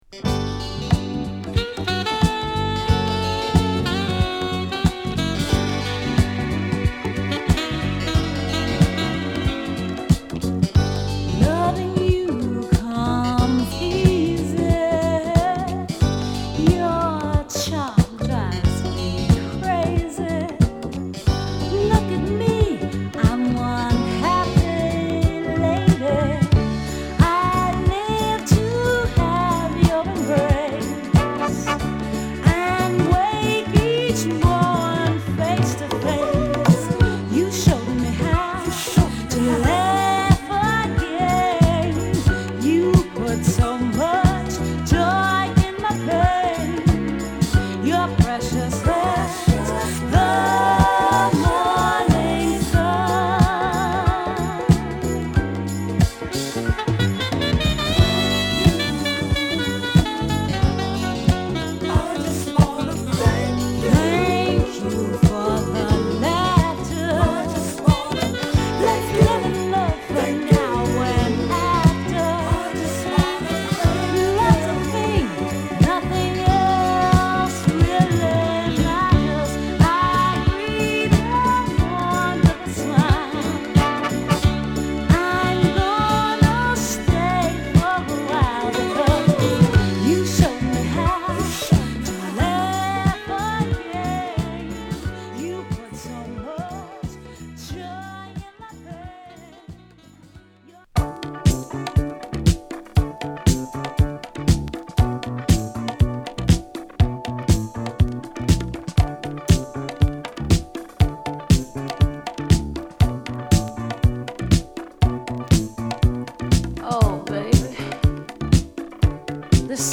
シメは極上のメロウ。